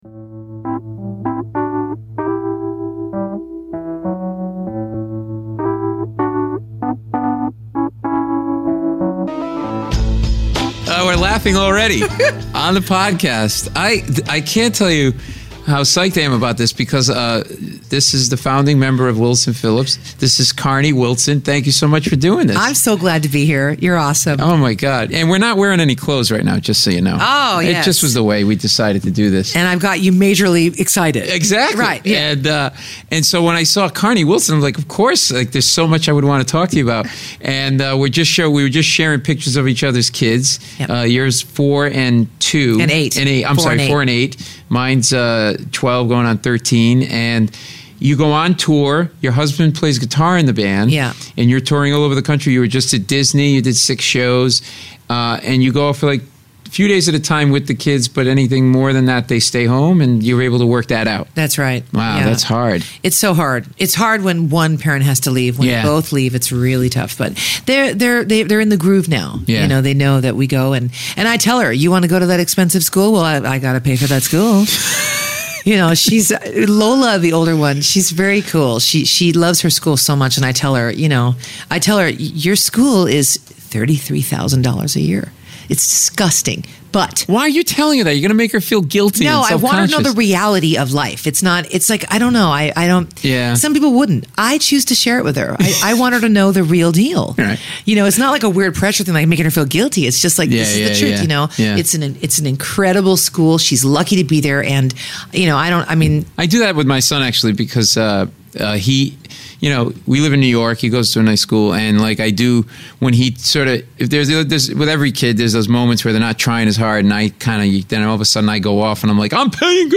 This conversation runs the gambit - from talking about forming her band and creating music, to finding her musical genius father in the pool with all his clothes on with "The Who's" Keith Moon, to recording her farts and playing them for us!